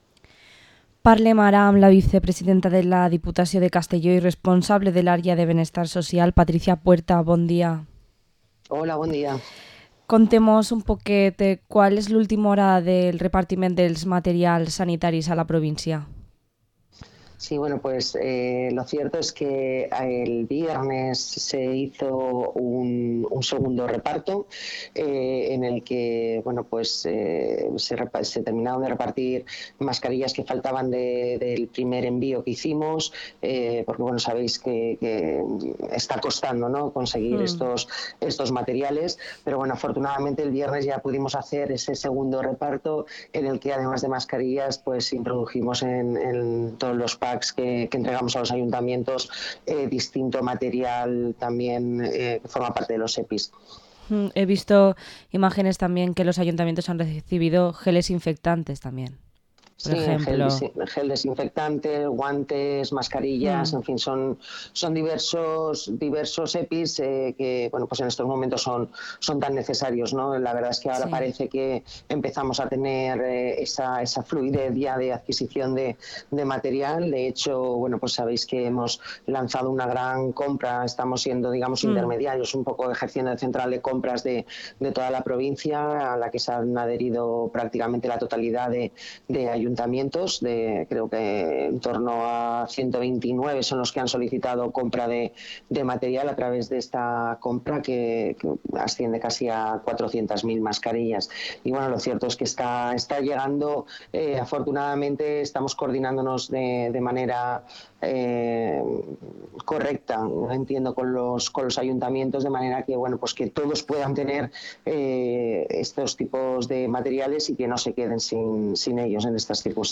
Entrevista a Patricia Puerta, vicepresidenta de la Diputación de Castellón